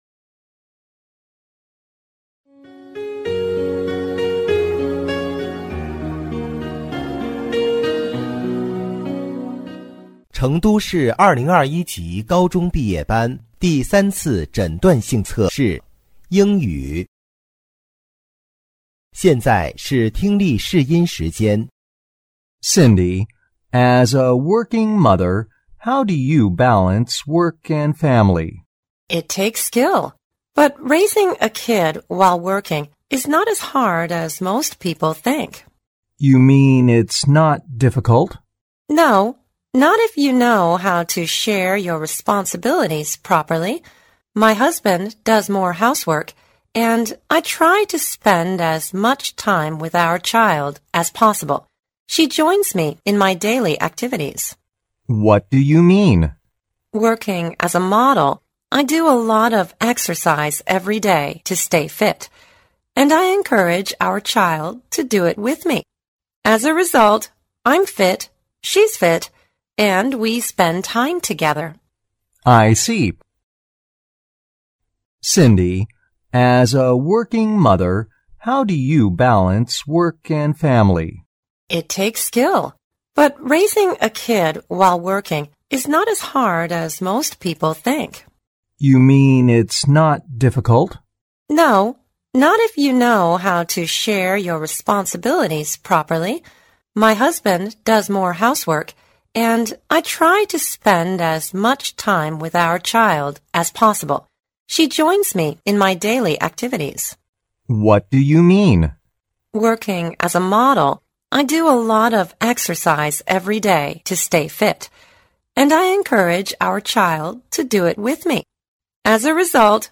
2024届成都三诊英语听力.mp3